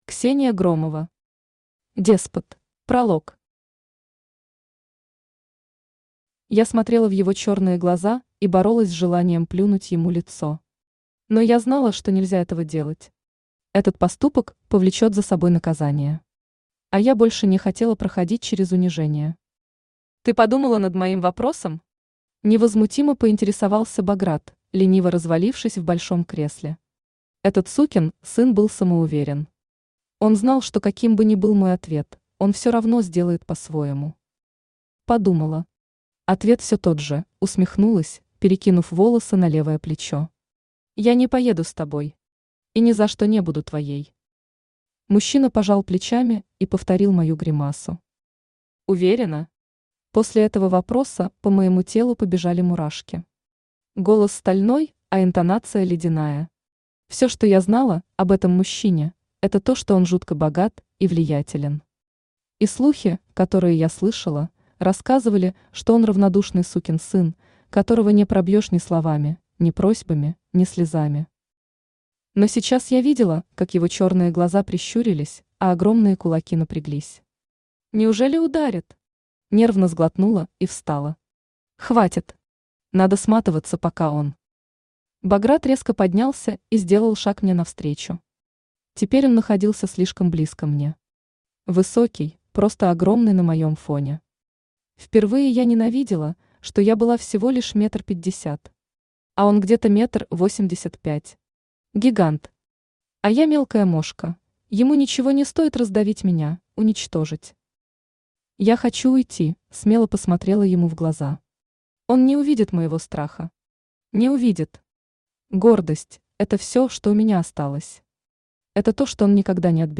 Aудиокнига Деспот Автор Ксения Громова Читает аудиокнигу Авточтец ЛитРес. Прослушать и бесплатно скачать фрагмент аудиокниги